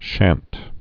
(shănt, shänt)